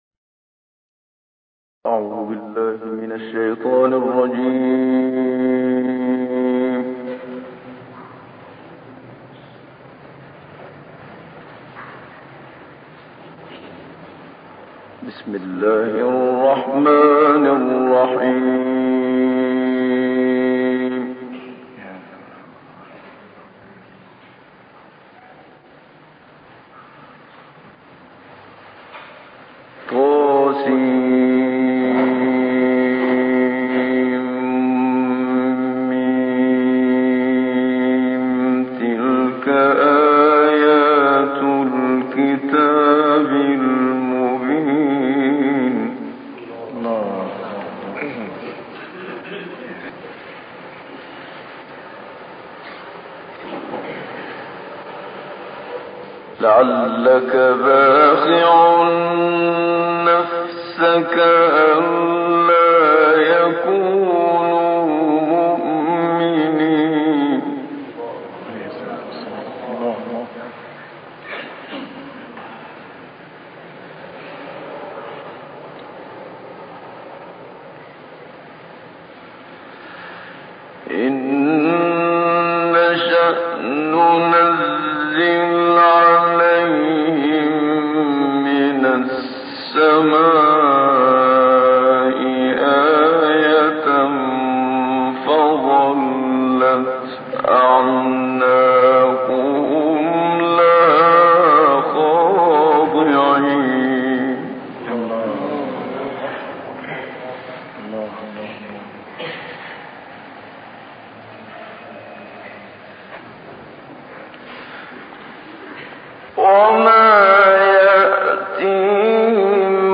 تلاوت سوره شعراء توسط استاد محمدصدیق منشاوی
قاری مصری